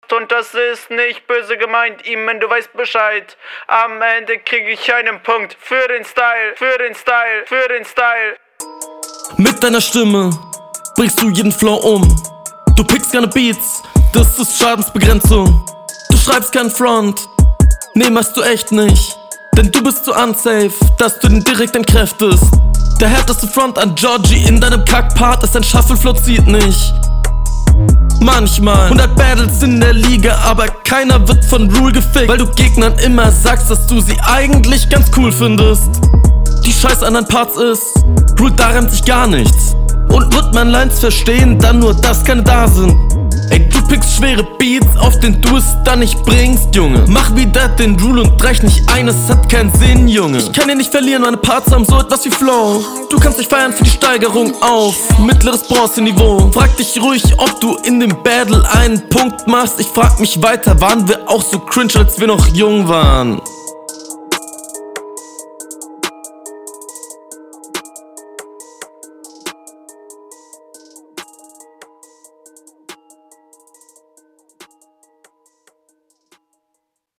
Flowlich ändert sich da nix.
Diesmal bessere Laier als sonst, Flow stabil eher schon Silberliga.